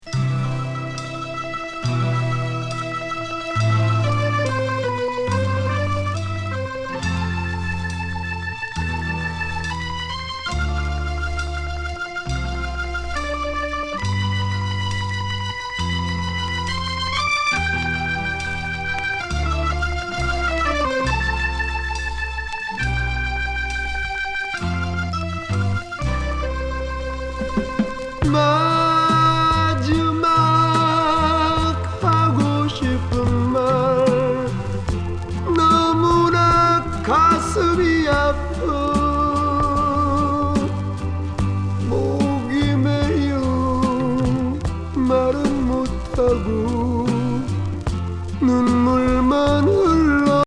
コリアン・フォーキーSSW歌謡。